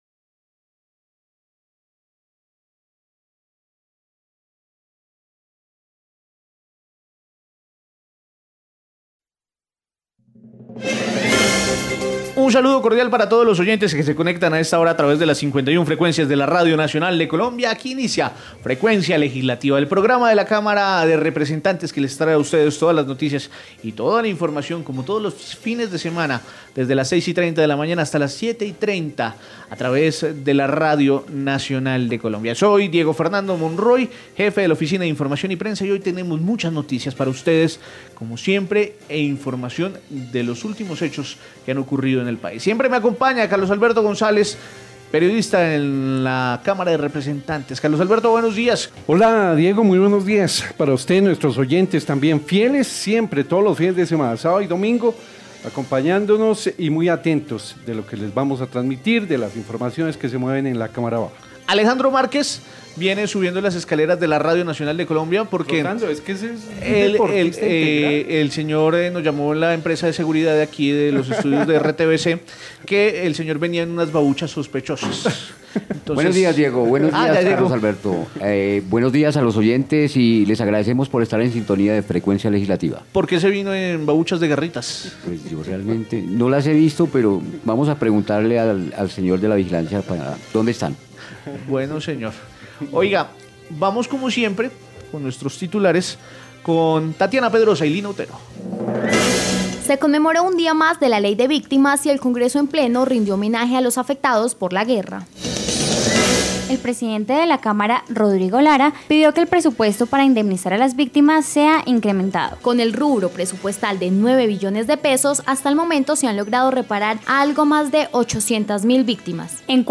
Programa Radial Frecuncia Legislativa Sabado 14 de Abril de 2018